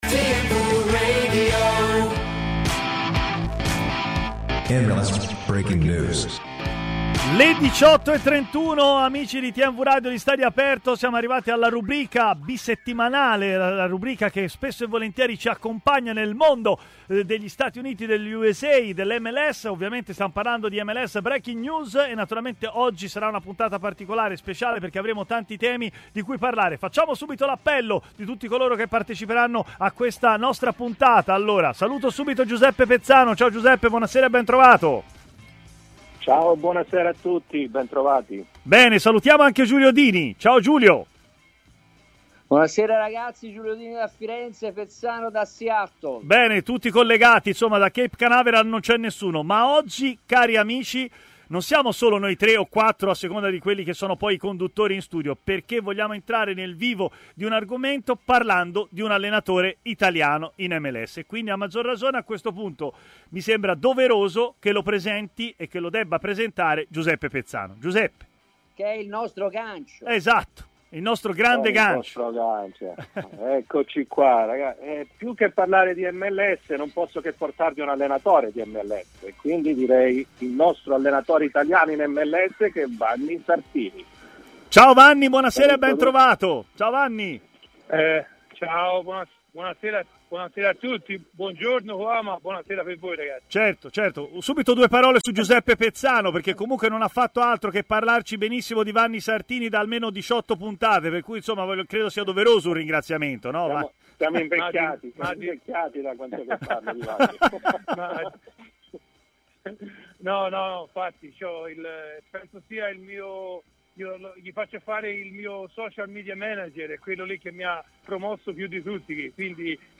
ha così parlato in diretta a MLS Breaking News, trasmissione di TMW Radio